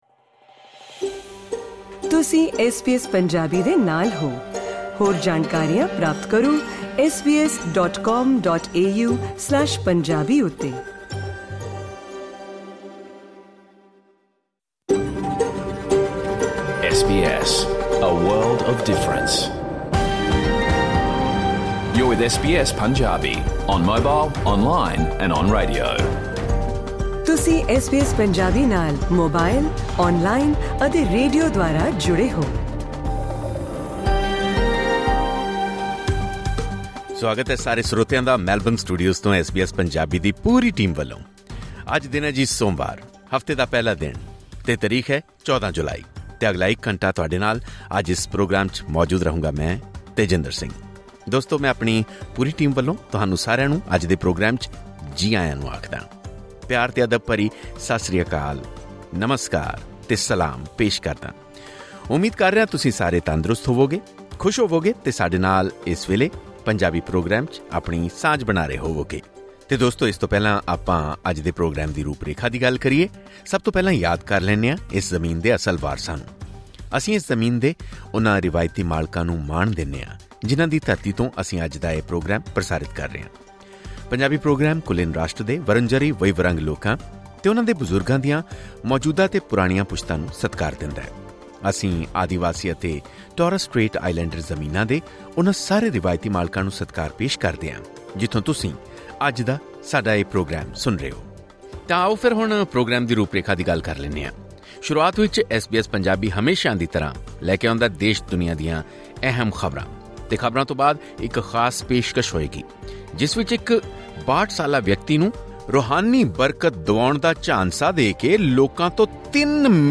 ਇੱਕ ਖਾਸ ਮੁਲਾਕਾਤ ਅਤੇ ਪ੍ਰੋਗਰਾਮ ਦੇ ਆਖਰੀ ਹਿੱਸੇ ਵਿਚ ਲੋਕਤੰਤਰ ਦੀ ਬਦਲਦੀ ਧਾਰਨਾ ਨਾਲ ਸਬੰਧਿਤ ਇੱਕ ਰਿਪੋਰਟ ਸ਼ਾਮਿਲ ਹੈ।